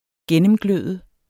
Udtale [ -ˌgløˀðə ]